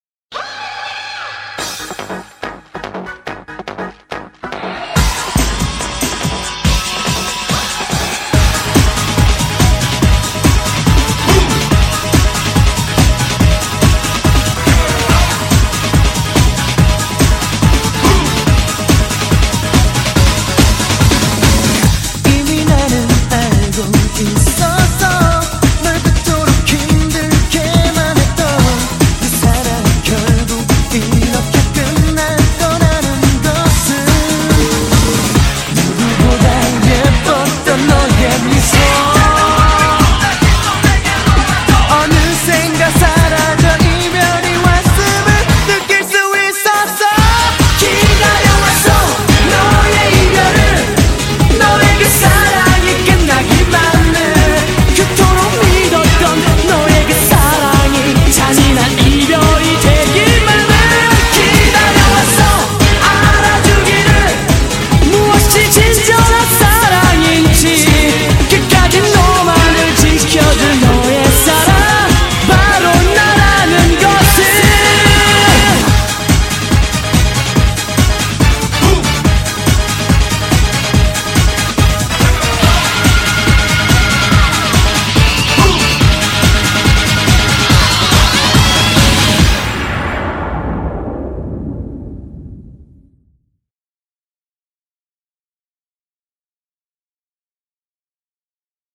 BPM147--1
Audio QualityPerfect (High Quality)